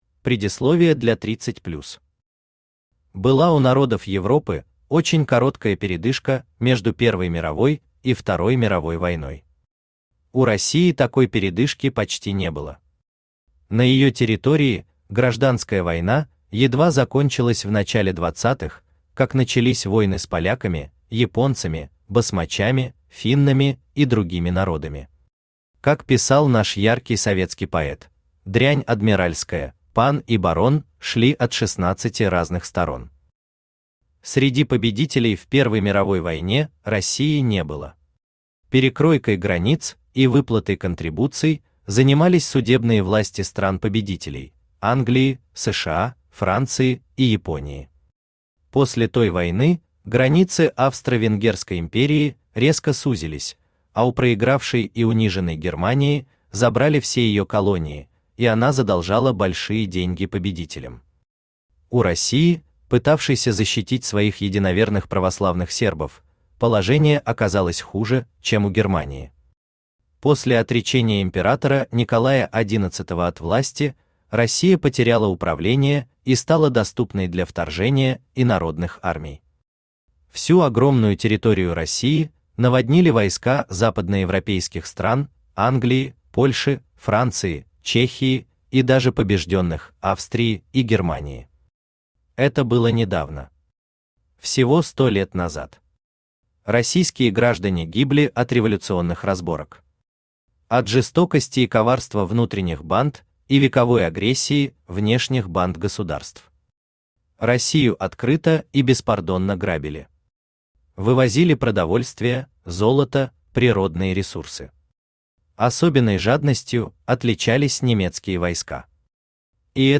Aудиокнига Абвер Автор Вадим Гринёв Читает аудиокнигу Авточтец ЛитРес.